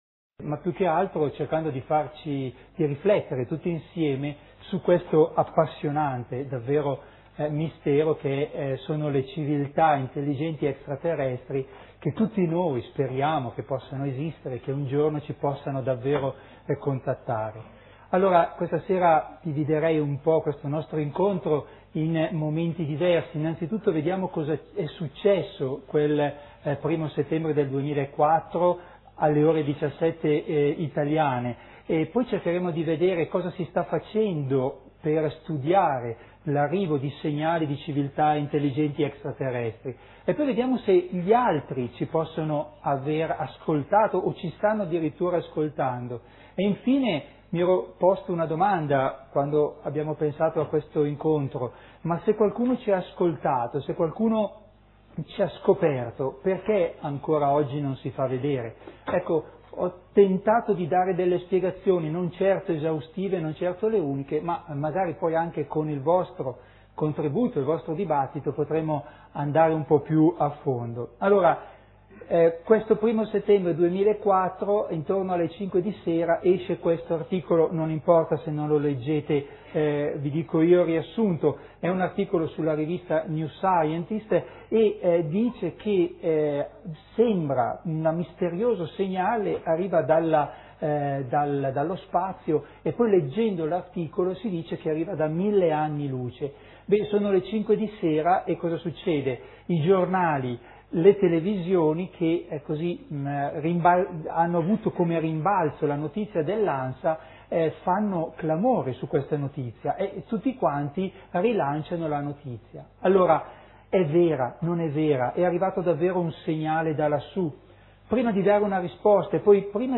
Titolo della conferenza: Ci hanno chiamato?